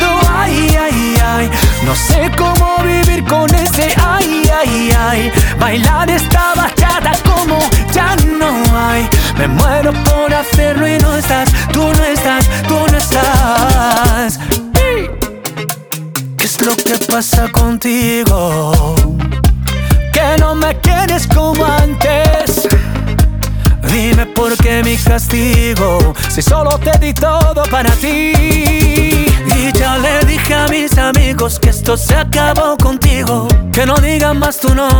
Жанр: Поп
# Pop in Spanish